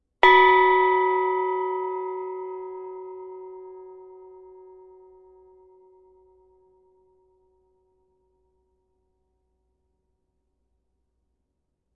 描述：铜锣被敲击的一面。
标签： 打击乐器
声道立体声